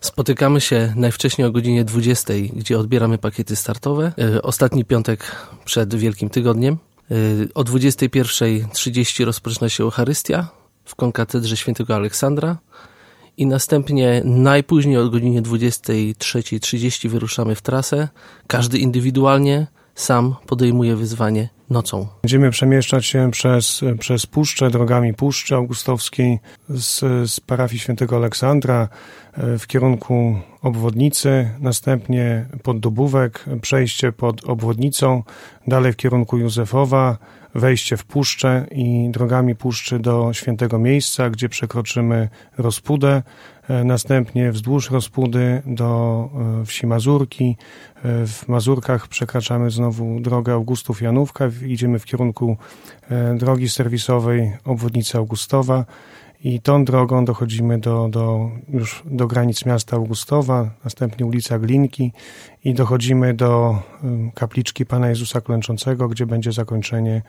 Trasa prowadzi z Suwałk do Augustowa. Szczegóły przedstawili na antenie Radia 5